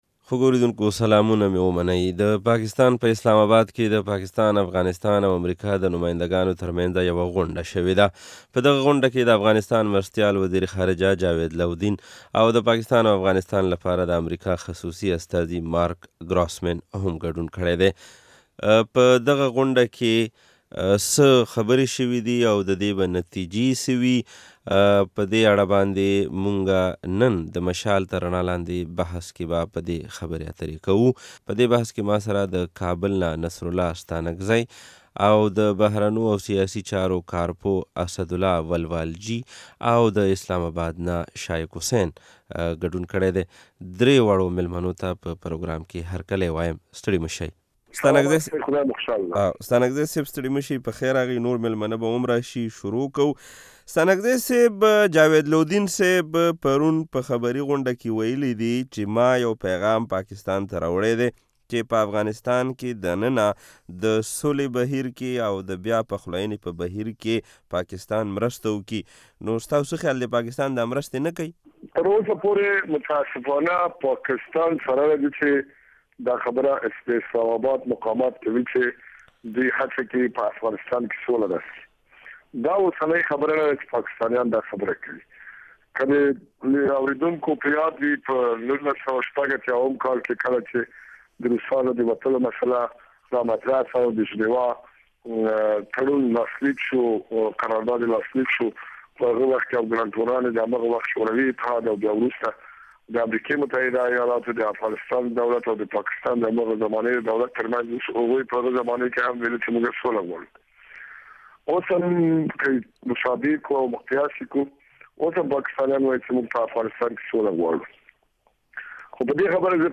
ګروسمین ويلي په افغانستان کې د پخلاينې په بهیر کې پاکستان یو خاص او مهم رول لري. د مشال تر رڼا لاندې اونیز بحث همدې موضوع ته ځانګړی شوی دی